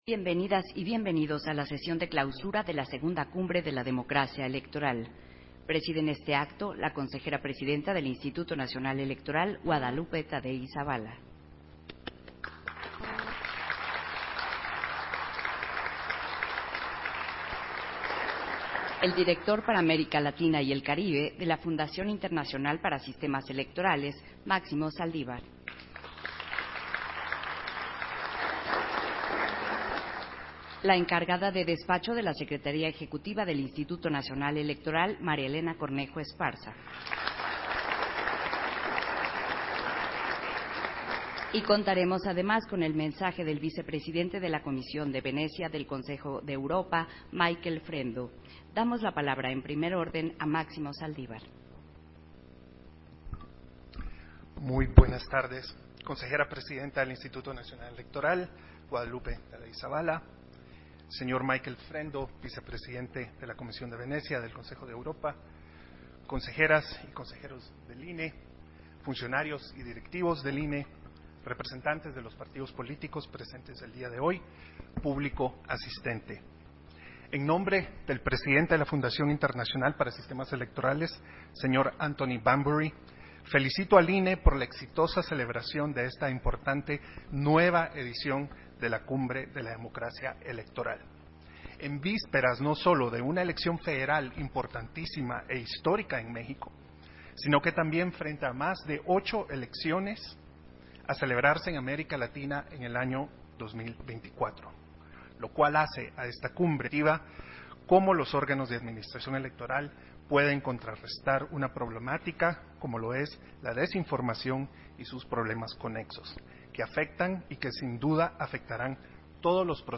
Versión estenográfica de la clausura de la II Cumbre de la Democracia Electoral